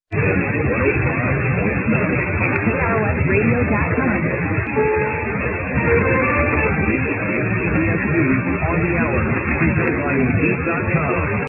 On October 3rd, He heard 1340 AM KROS top of the hour identification. The frequency 1340 AM is very crowded and stations have low power, So he was especially lucky to catch the signal here (in Finland).